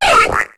Cri de Canarticho dans Pokémon HOME.